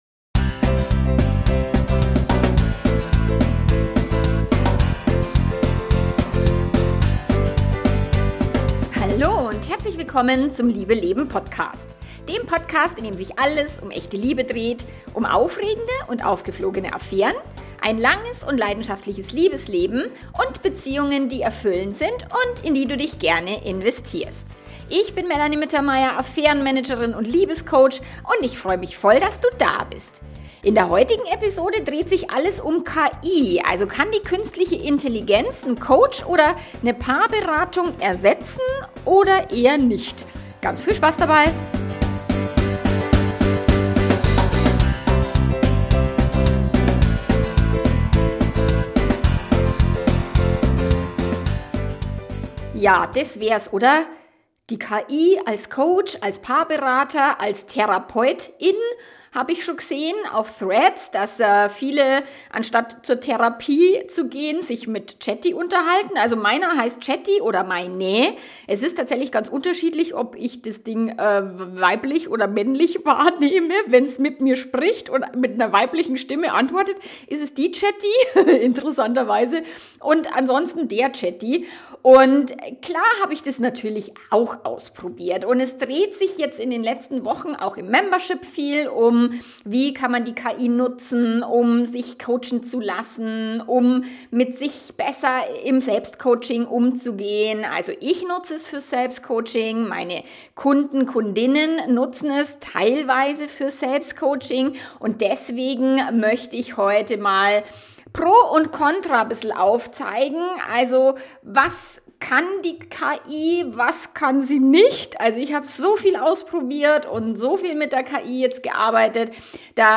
In dieser Folge sprechen wir mit Mitgliedern des Liebe Leben Premium Memberships, die genau diesen Wandel erlebt haben.